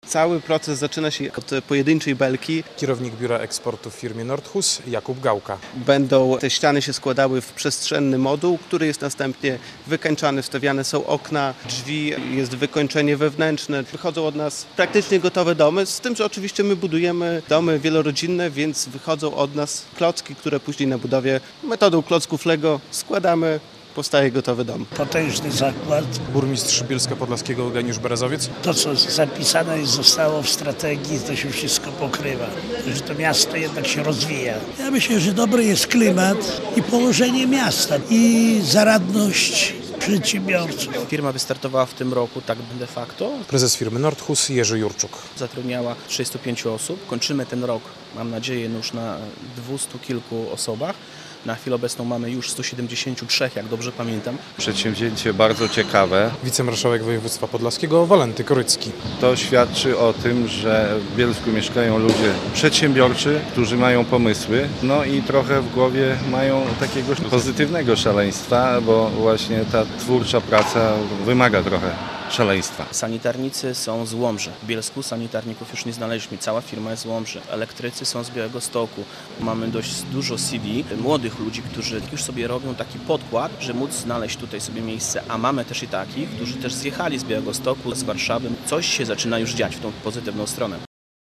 Otwarcie fabryki firmy Nordhus w Bielsku Podlaskim - relacja